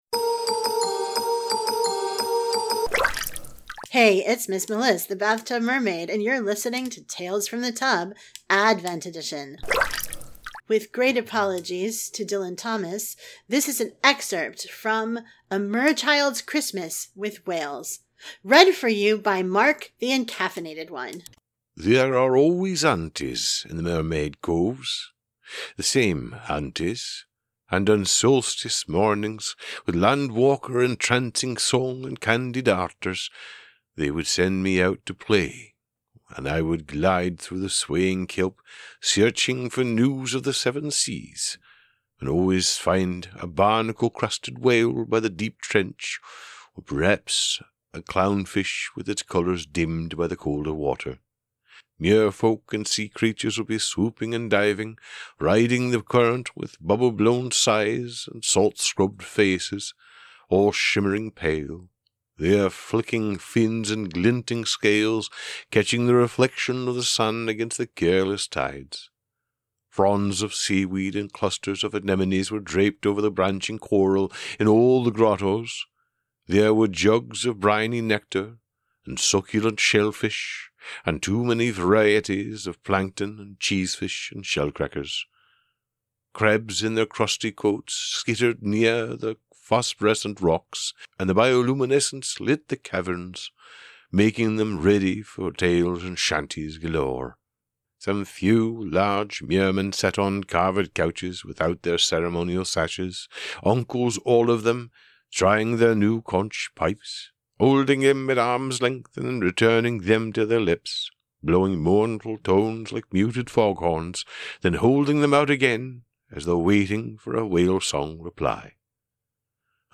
Guest Voices:
• Sound Effects are from Freesound.